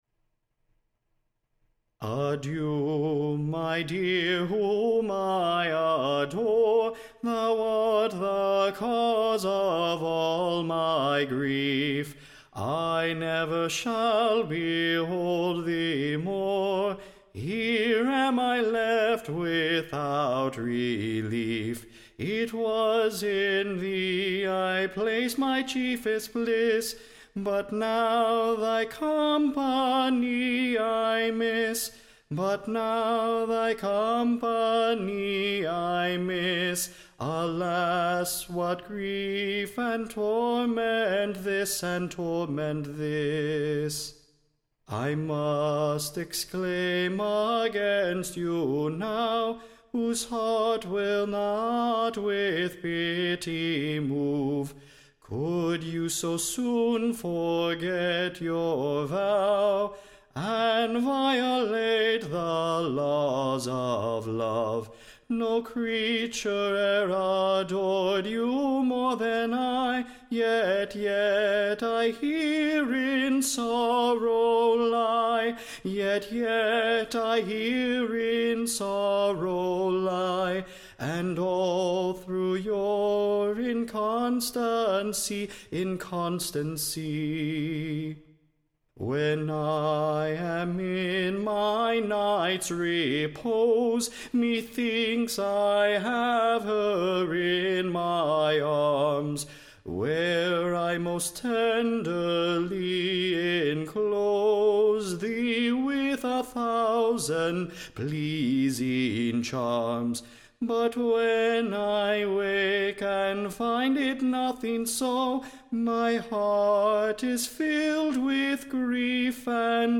Recording Information Ballad Title The Dispairing Youths Grief / Crowned with Joy and Happiness, / By the Return of his Dear LOVE.
Tune Imprint To the Tune of, Black and Sullen hour.